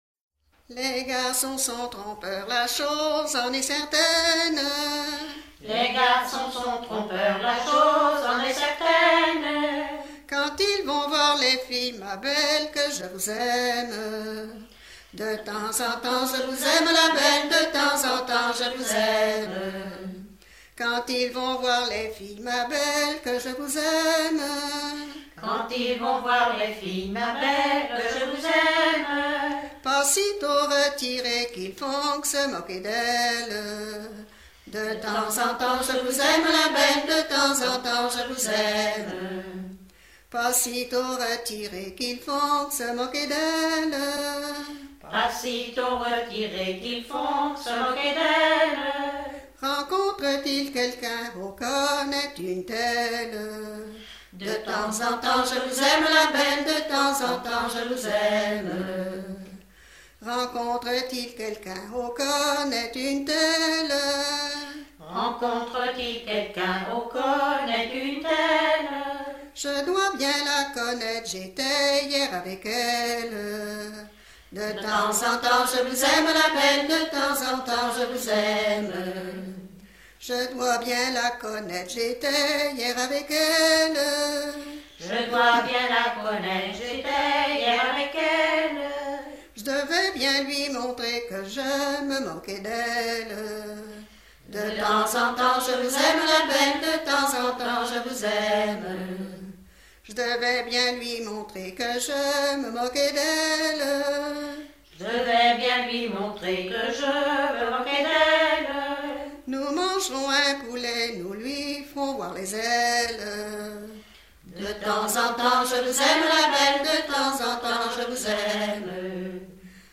Localisation Bruffière (La)
Genre laisse